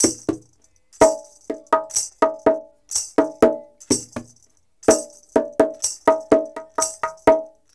124-PERC5.wav